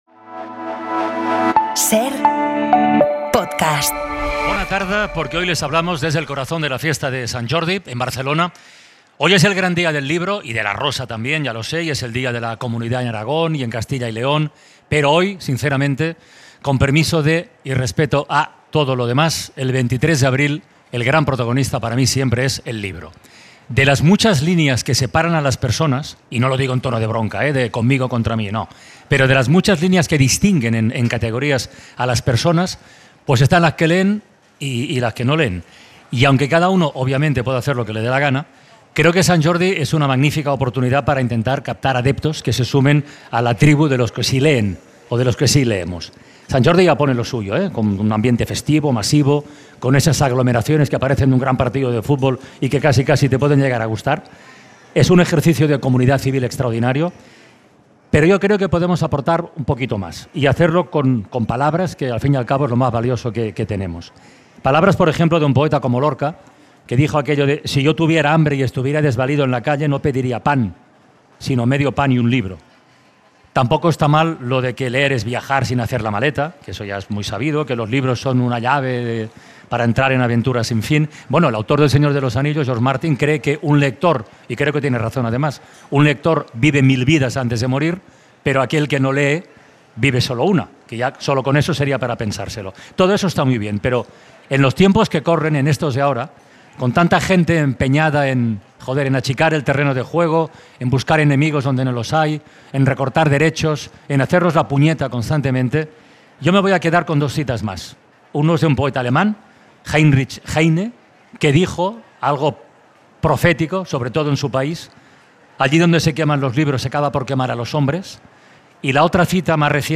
Carles Francino dirige una edición especial de La Ventana desde Barcelona por Sant Jordi.